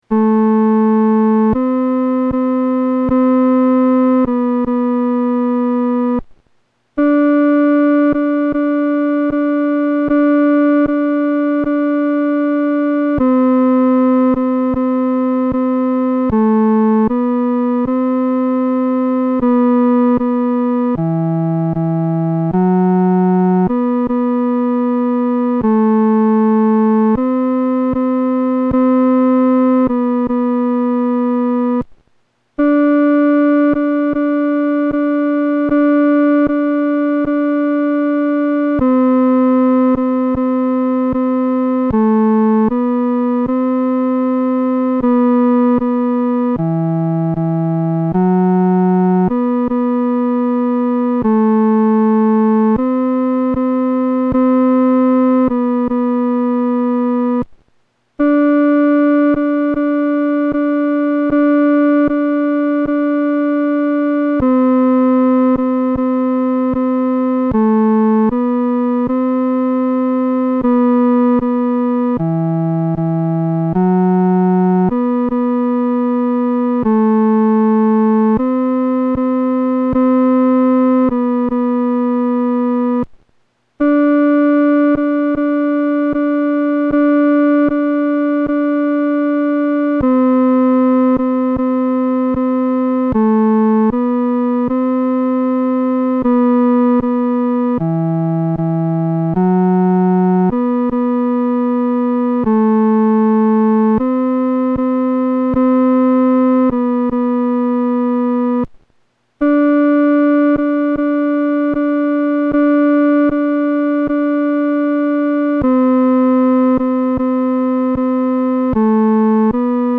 伴奏
男高